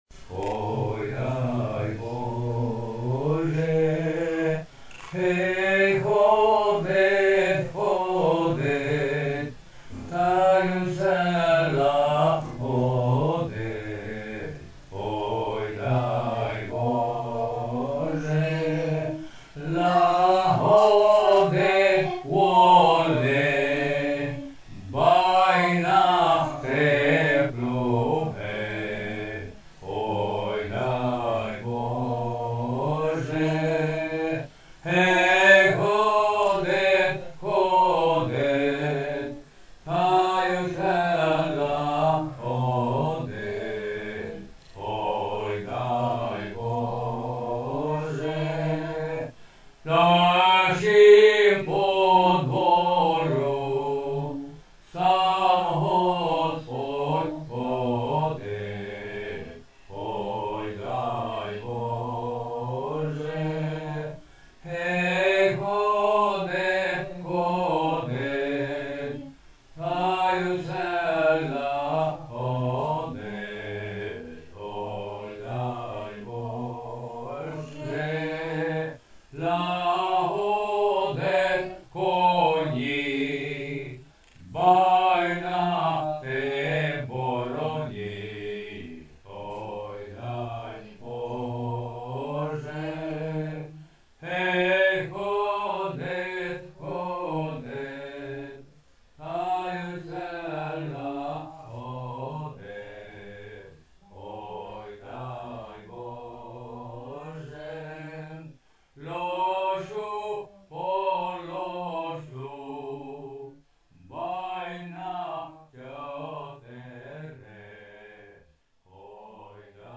Colinda veche